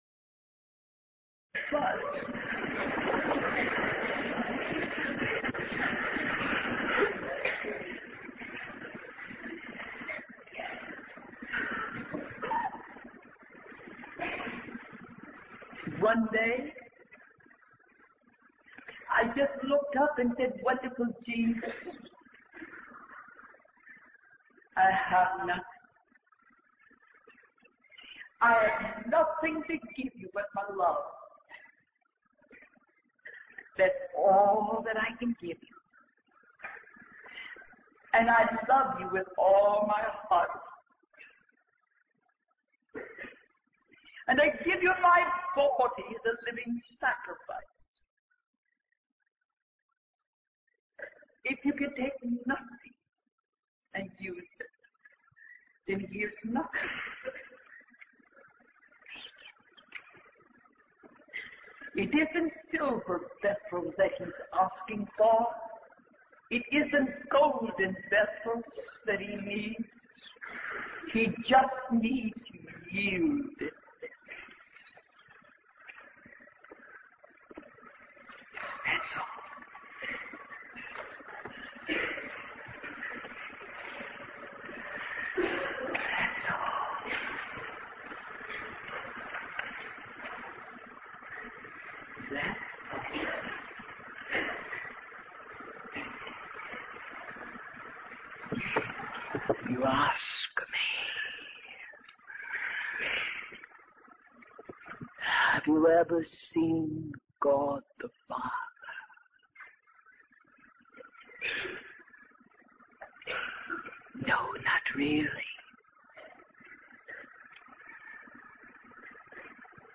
1974 Jerusalem Conference - Part 2 by Kathryn Kuhlman | SermonIndex